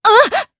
Seria-Vox_Damage_jp_03.wav